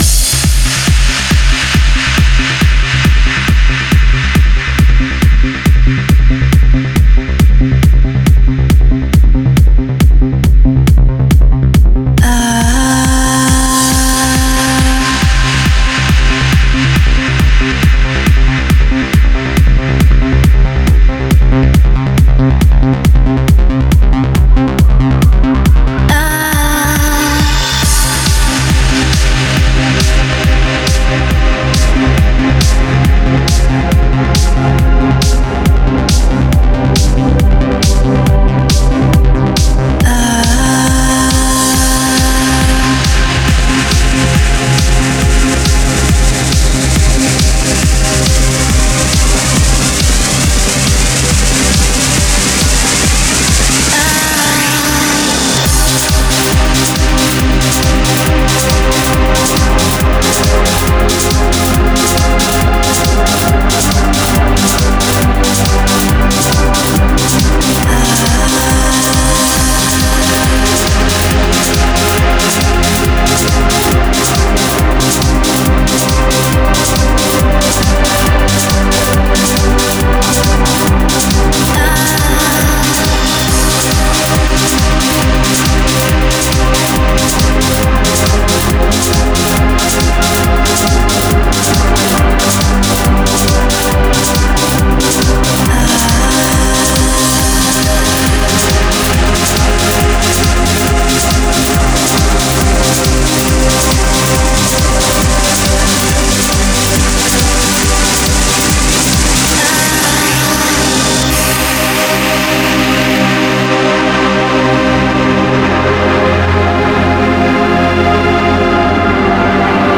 Стиль: Trance / Uplifting Trance